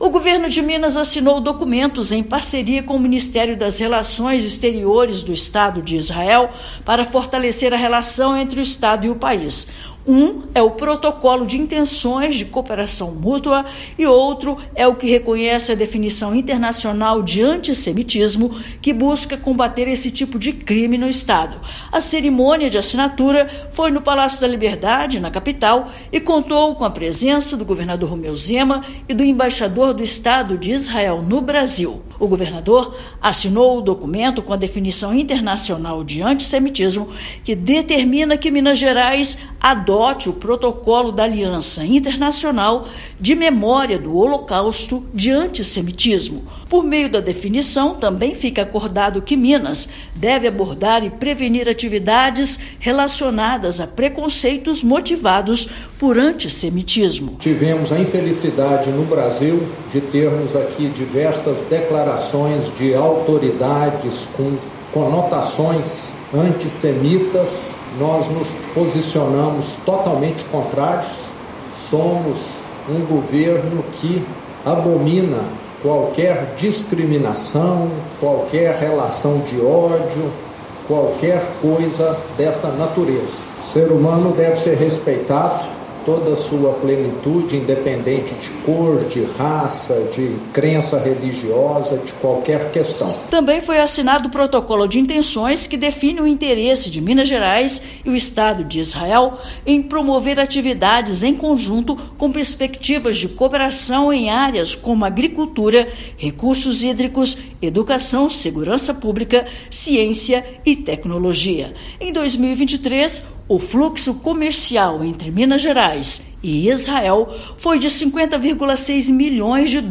Protocolos vão reforçar a parceria entre o estado e o país na área econômica e contra o preconceito. Ouça matéria de rádio.